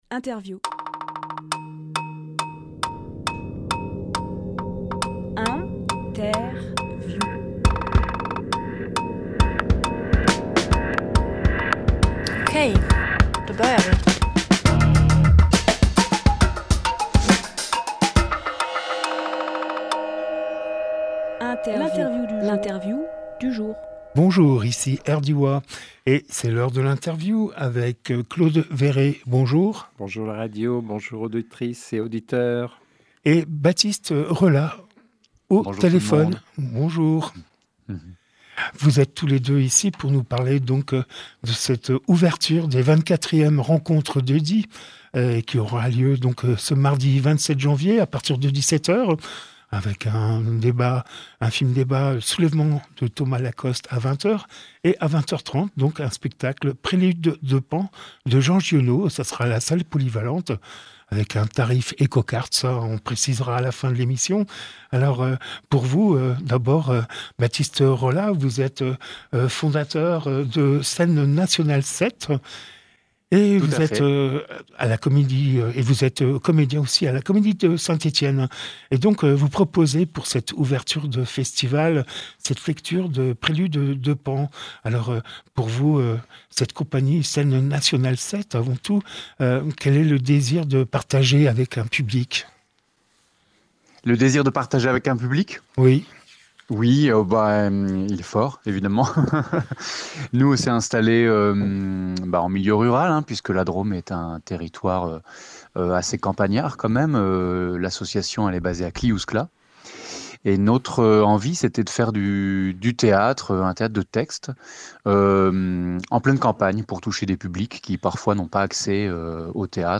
Emission - Interview Prélude de Pan Publié le 19 janvier 2026 Partager sur…
lieu : Studio RDWA